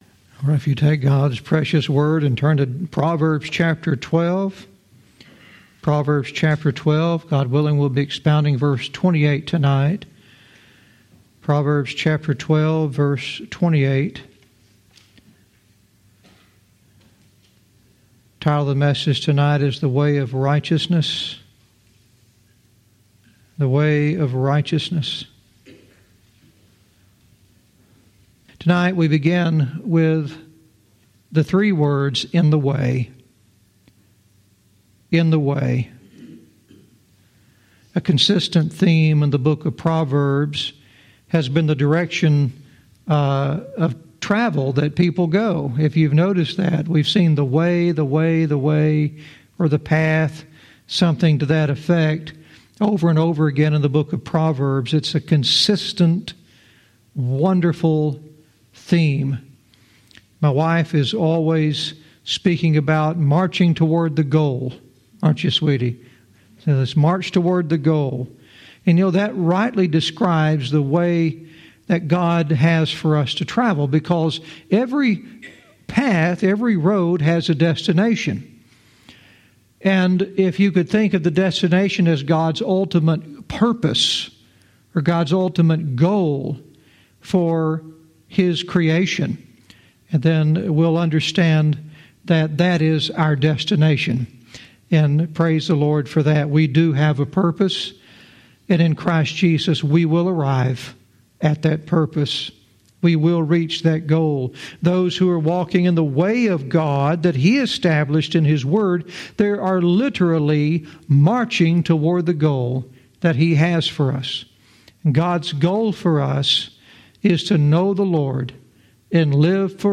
Verse by verse teaching - Proverbs 12:28 "The Way of Righteousness"